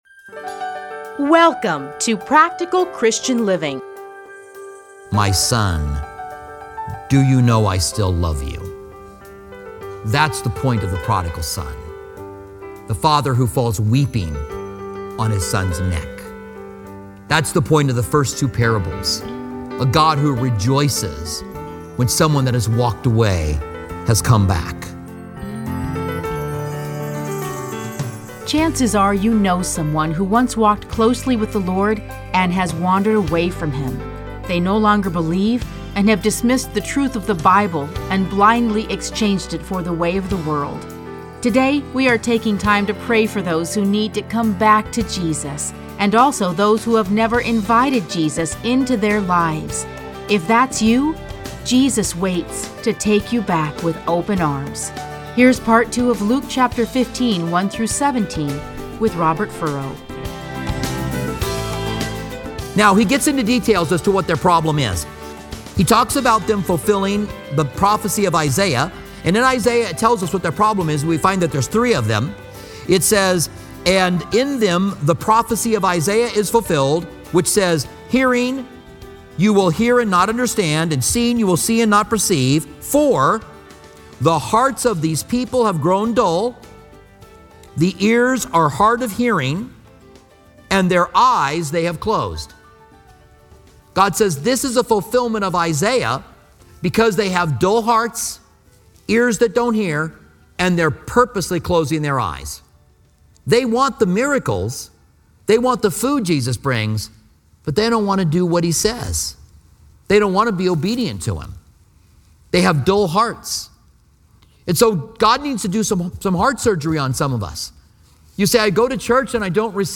Listen to a teaching from Luke 15:1-7.